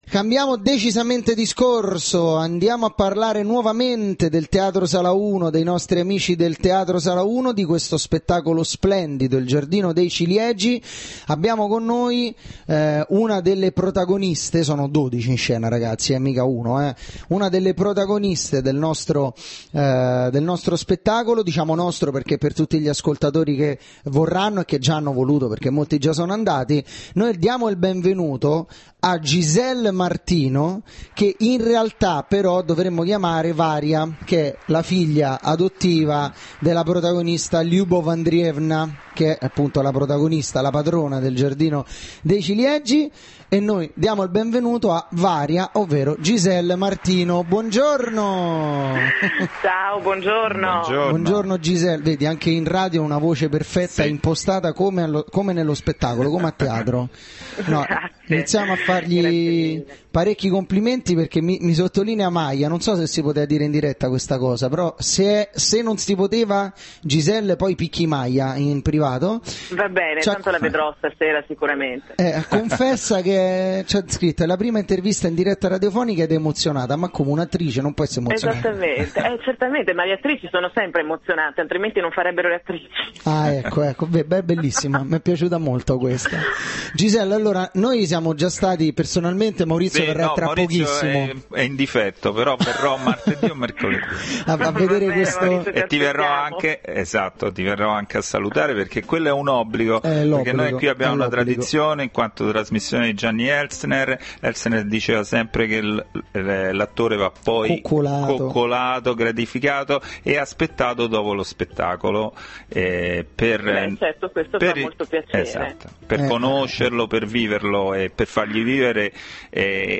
Intervento telefonico